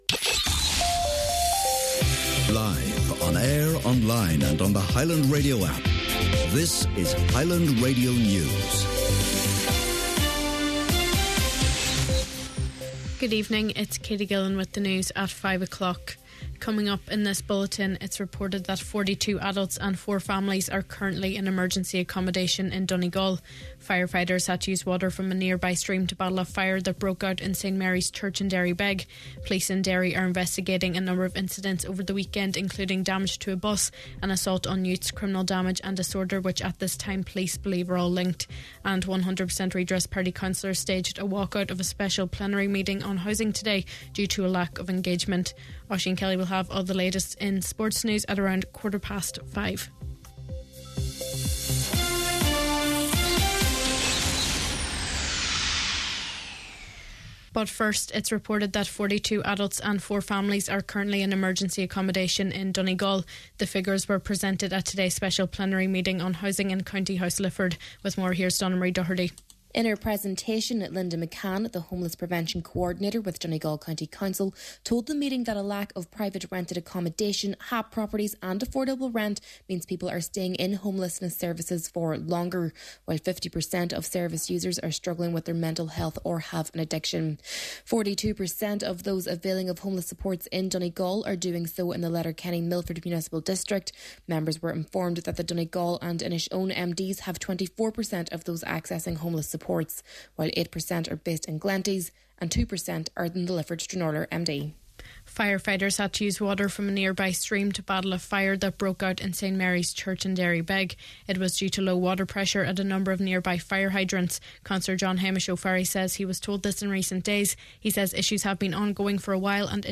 Main Evening News, Sport and Obituaries – Monday April 28th